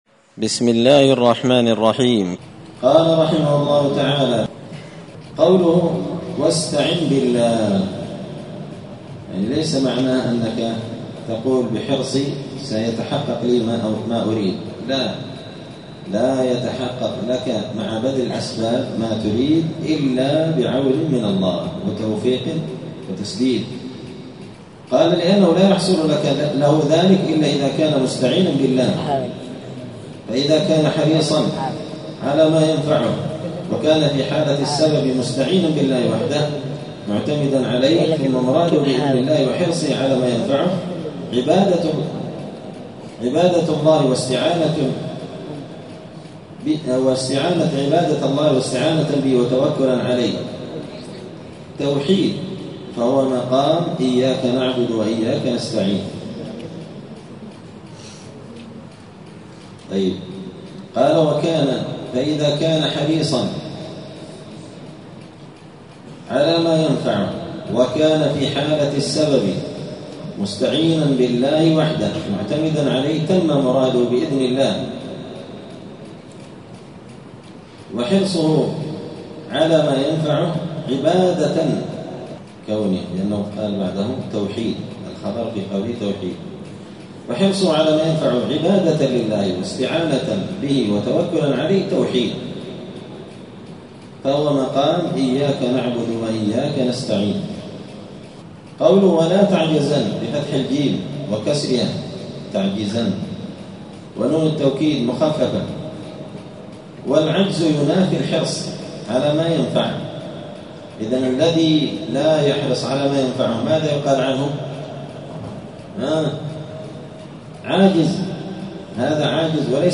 دار الحديث السلفية بمسجد الفرقان قشن المهرة اليمن
*الدرس الثامن والثلاثون بعد المائة (138) {تابع لباب ما جاء في اللو}*